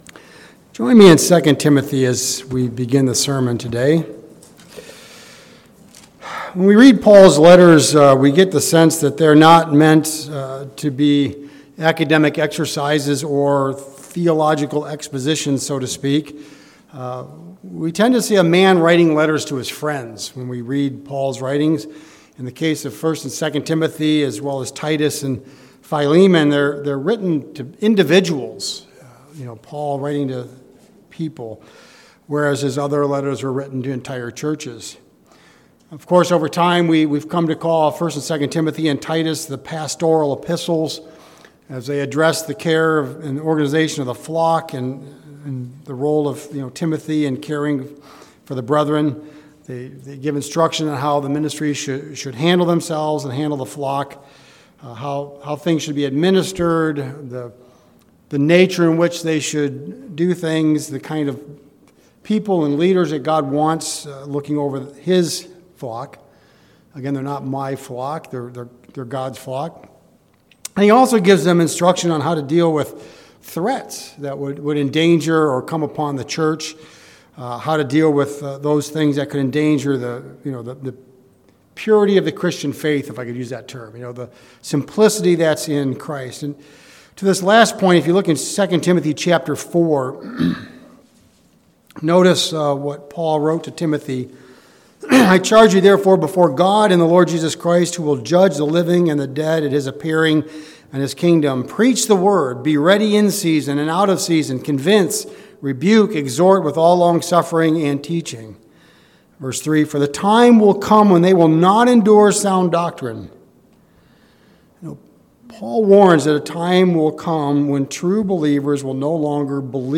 In this sermon, we will cover 3 common heresies: (1) Judaism ~ i.e. "Messianic Jews", (2) Gnosticism, and (3) Docetism.
Given in Mansfield, OH